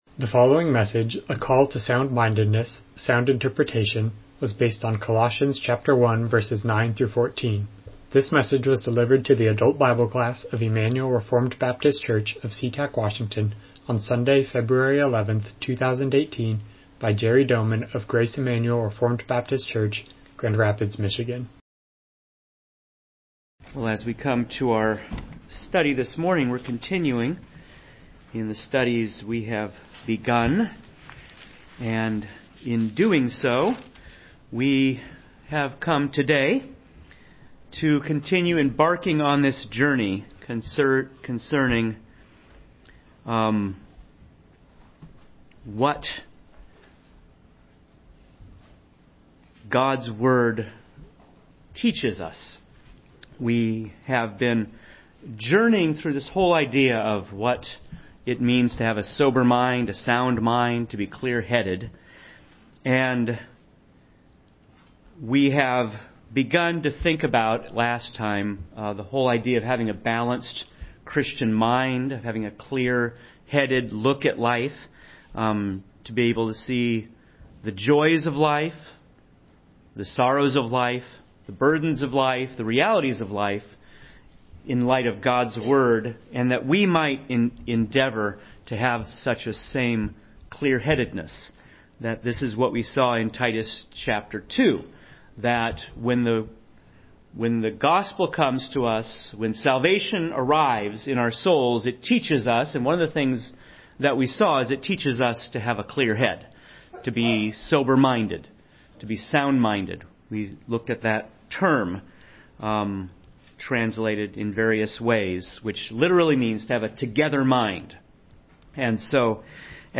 Passage: Colossians 1:9-14 Service Type: Sunday School